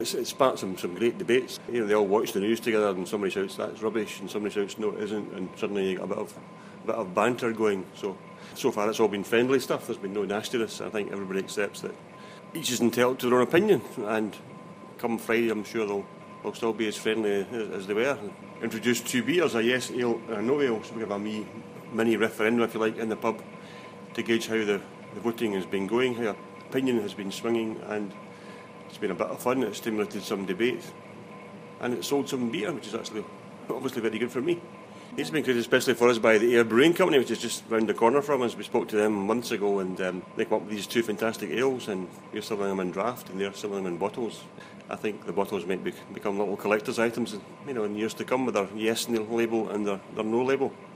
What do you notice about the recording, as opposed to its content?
Talks about the Independence Referendum in the pub